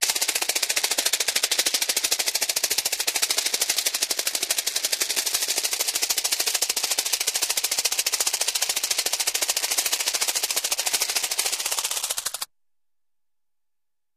RapidInsectWing CRT042204
Steady, Rapid, Insect-like Wing Flaps; Speeds Up At End